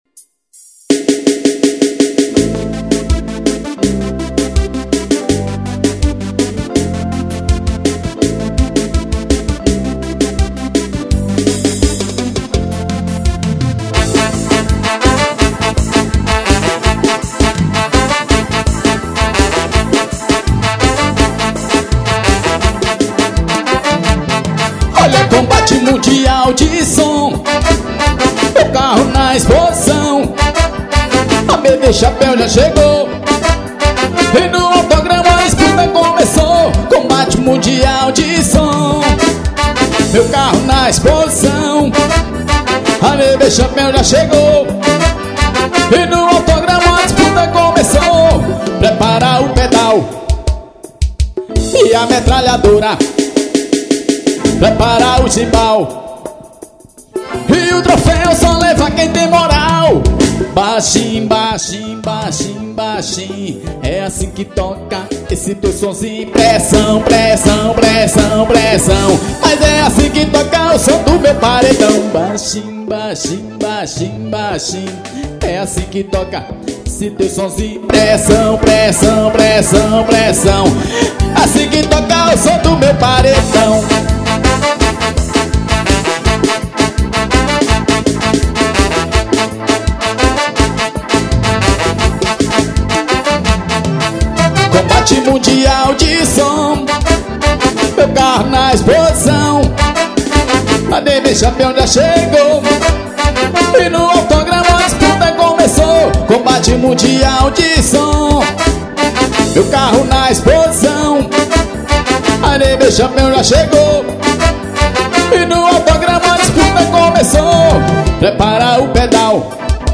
forró pegado.